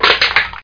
CRUSH1.mp3